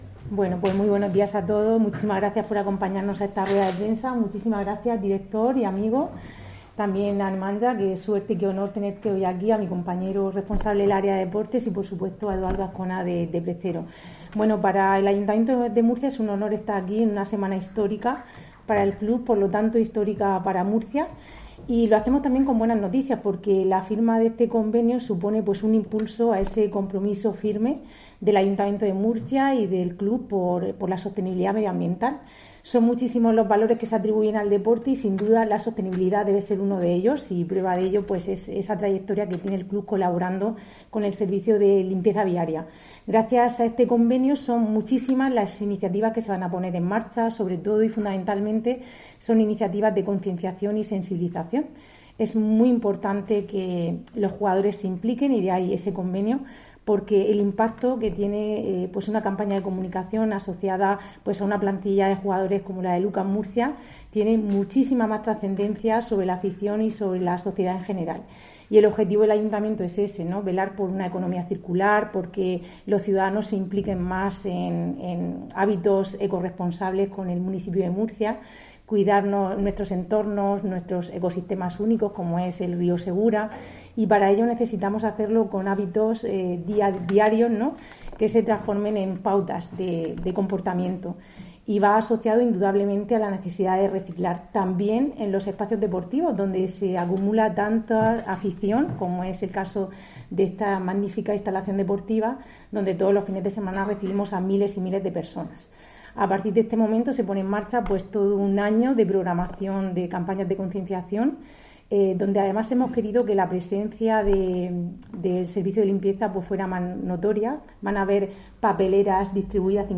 Rebeca Pérez, vicealcaldesa y concejal de Fomento y Patrimonio